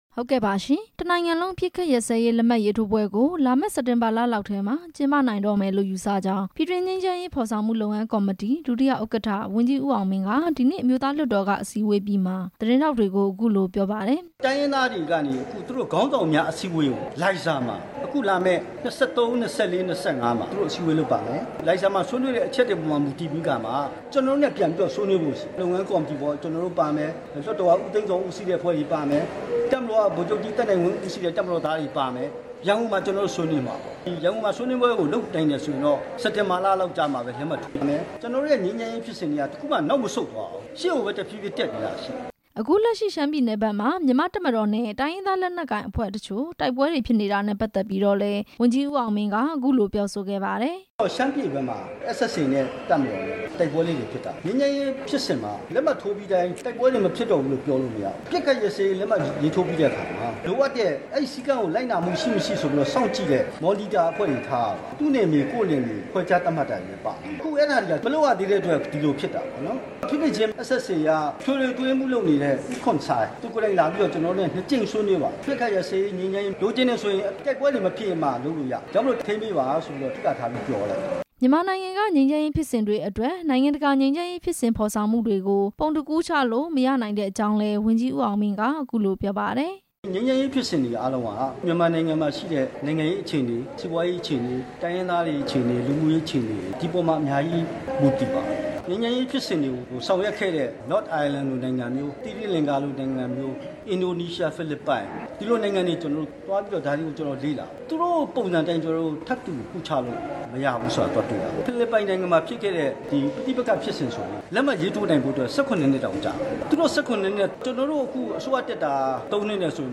၀န်ကြီးဦးအောင်မင်း လွှတ်တော်မှာ ရှင်းလင်းချက်